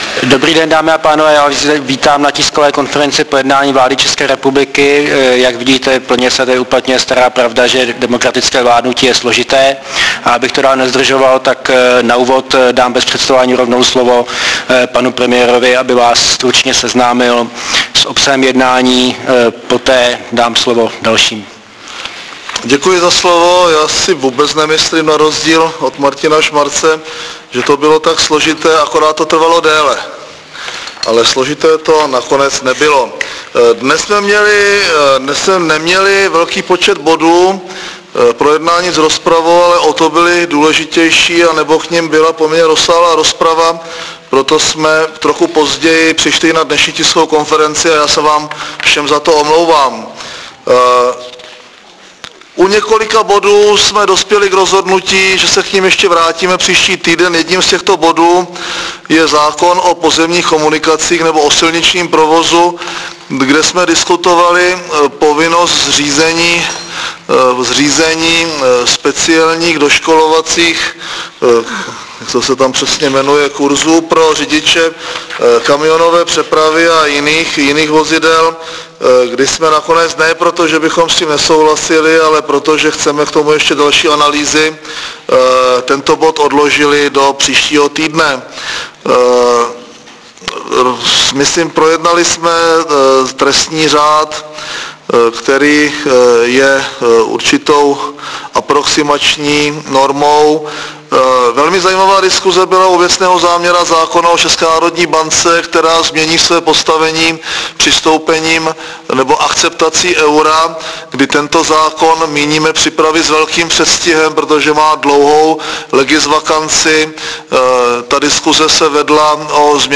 Tisková konference po zasedání vlády v pondělí 19.3.2007